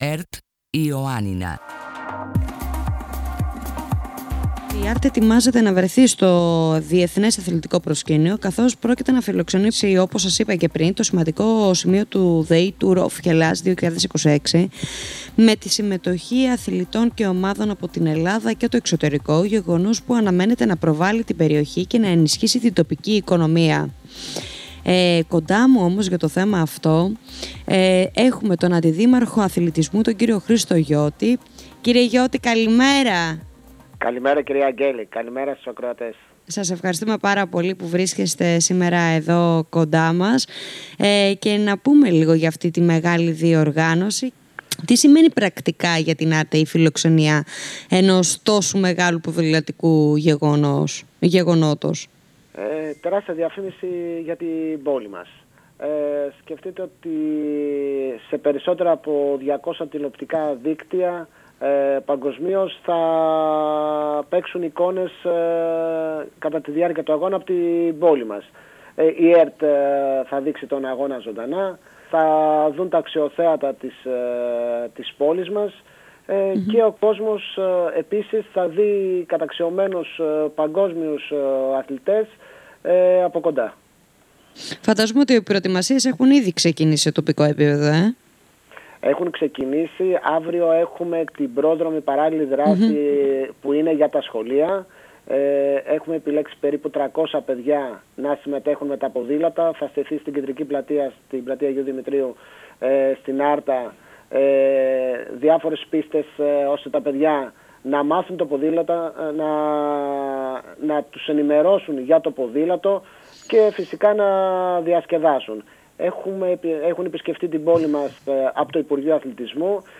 Η Άρτα πρόκειται να αποτελέσει κεντρικό σημείο του ΔΕΗ Tour of Hellas 2026, μιας διεθνούς ποδηλατικής διοργάνωσης με συμμετοχές αθλητών από την Ελλάδα και το εξωτερικό, γεγονός που θα συμβάλει στην ανάδειξη της περιοχής και την ενίσχυση της τοπικής οικονομίας. Σχετικά με τις προετοιμασίες και τη σημασία του γεγονότος, μίλησε στο «Πρωινό Ραντάρ» ο Αντιδήμαρχος Αθλητισμού και Παιδείας του Δήμου Αρταίων, κ. Χρήστος Γιώτης.